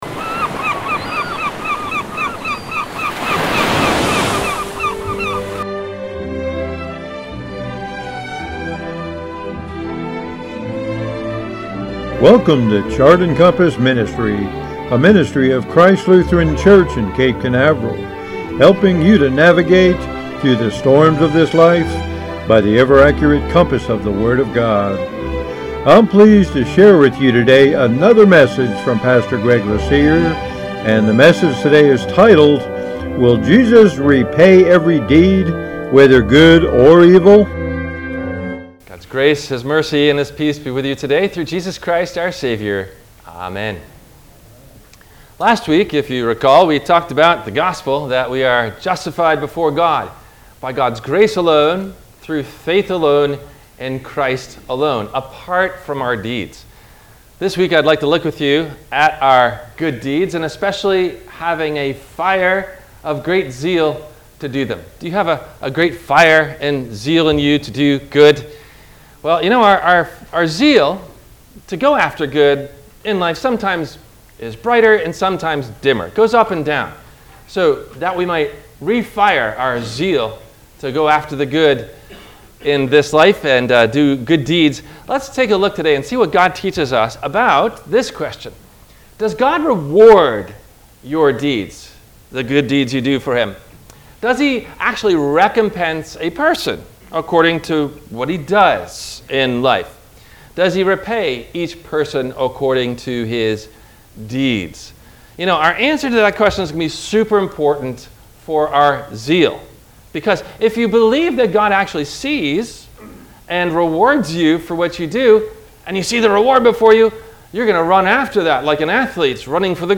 Will Jesus Repay Every Deed, Whether Good Or Evil? – WMIE Radio Sermon – November 27 2023
No Questions asked before the Sermon message: